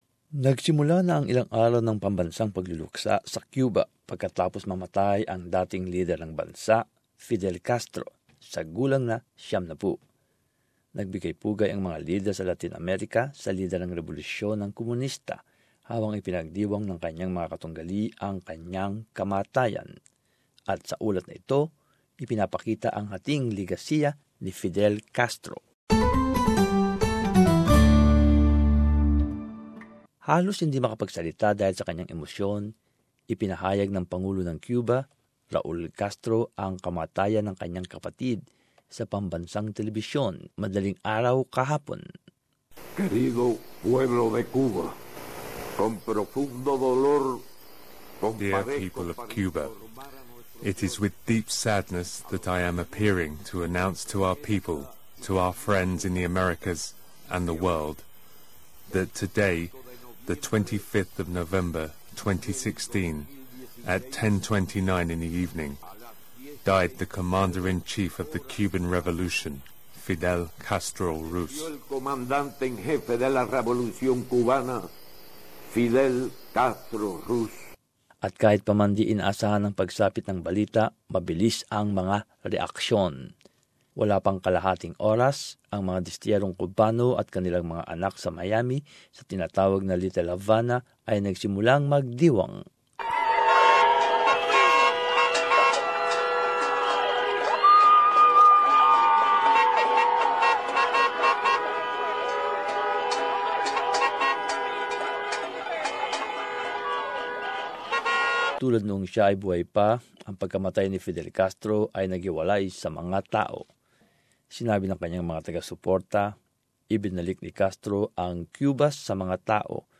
As this report shows, Fidel Castro leaves behind a mixed legacy.